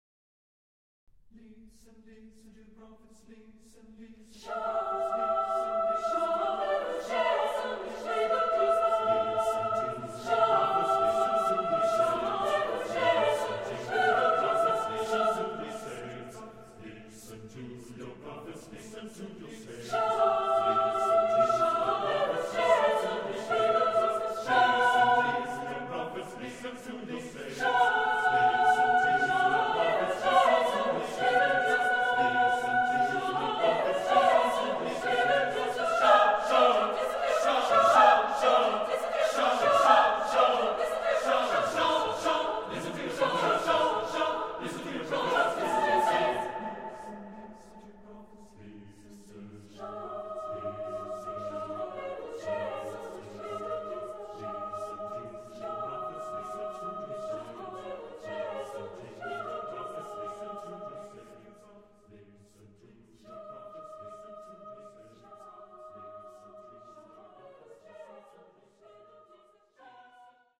Cantata
(a cappella)